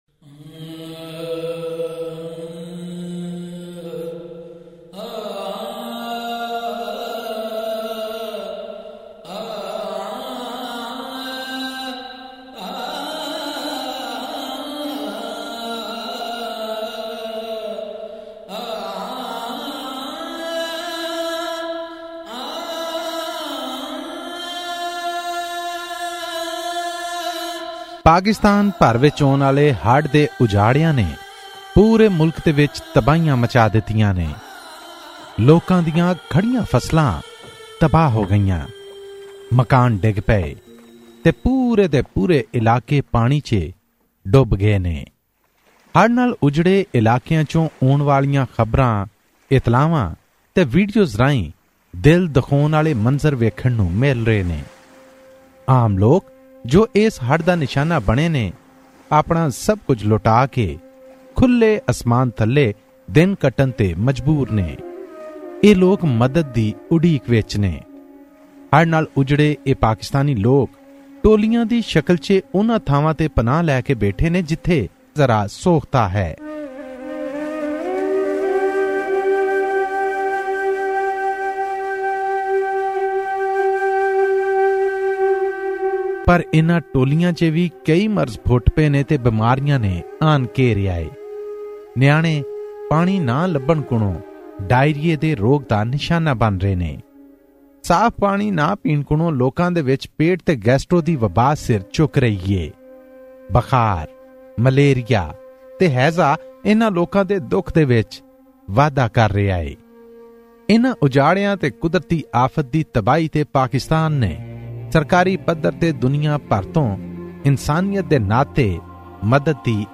special ground report